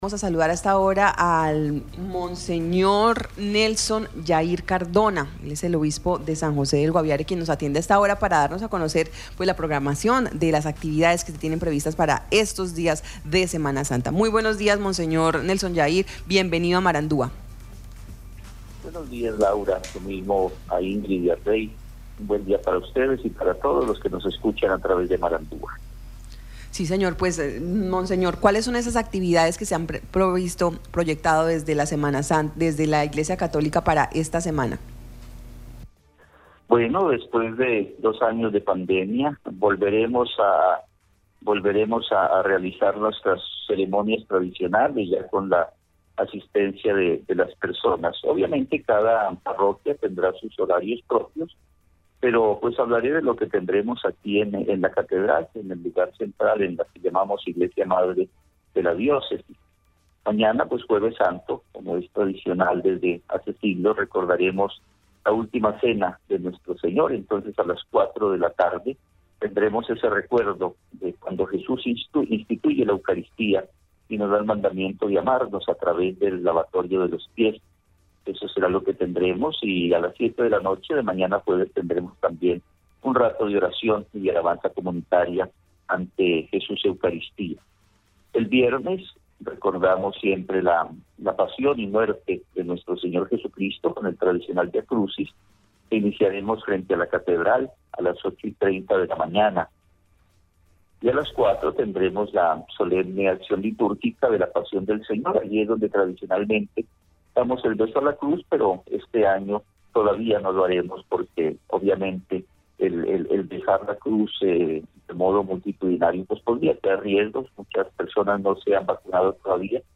Escuche a monseñor Nelson Jair Cardona, obispo Diócesis San José del Guaviare.